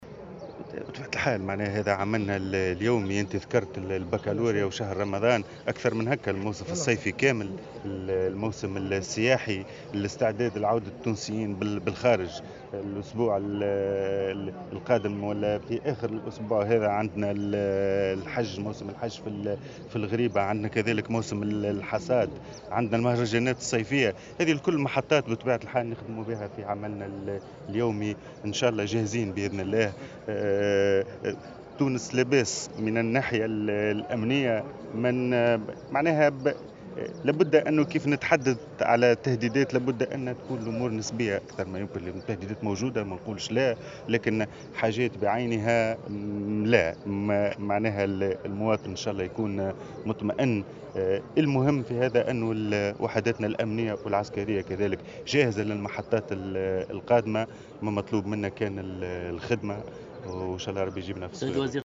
وأضاف الوزير في تصريح لمراسل "الجوهرة أف أم" على هامش زيارة أداها اليوم إلى المدرسة الوطنية لتكوين رقباء الأمن بسيدي سعد بالقيروان أن العمل متواصل من أجل تأمين الموسم السياحي وموسم حج اليهود إلى الغريبة والمهرجانات الصيفية وكذلك الامتحانات الوطنية.